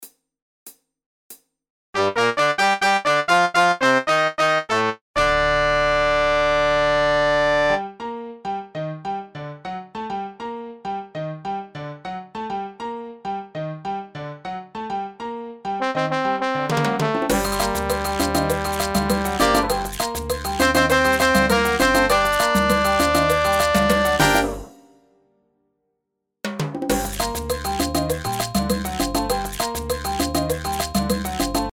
Nuestros midis NO incluyen linea melodica, No incluye letra.